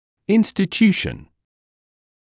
[ˌɪnstɪ’tjuːʃn][ˌинсти’тйуːшн]организация, учреждение; создание, образование; институт